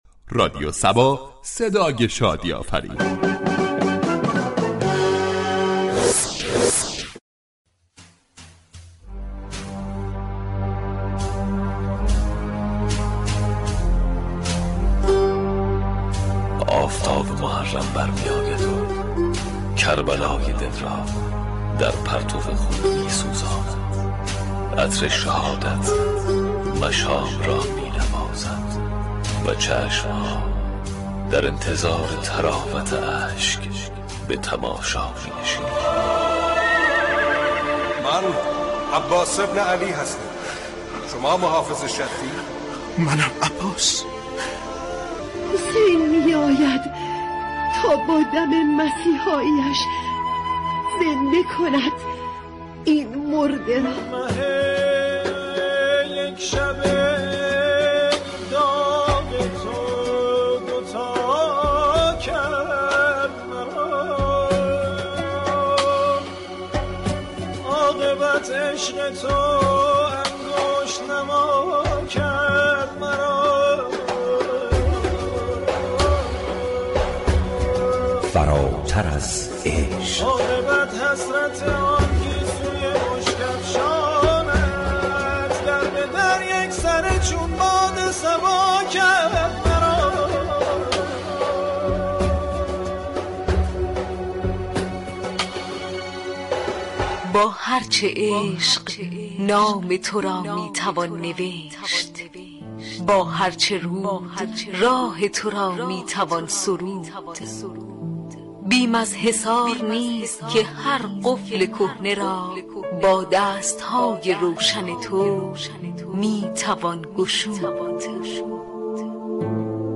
فراتر از عشق با پخش سكانس هایی از فیلم های مناسبتی و پخش ترانه تقدیم مخاطبان می شود .